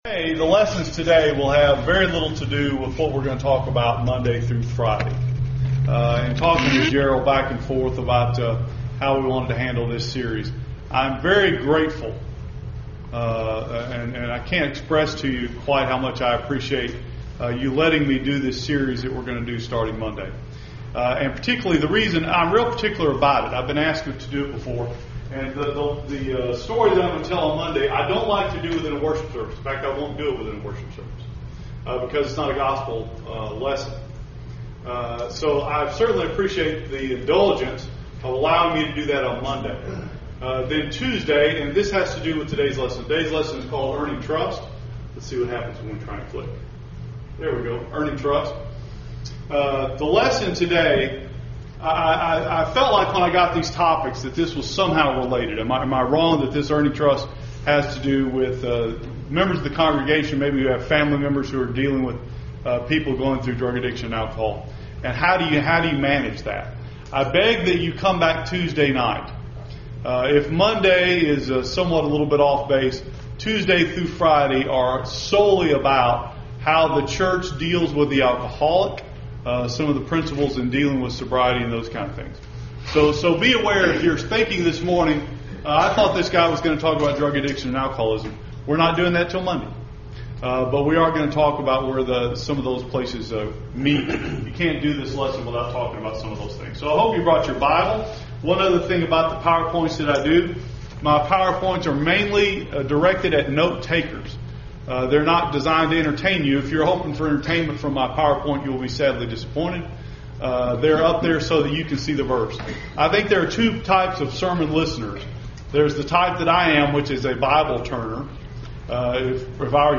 Audio Sermon Files.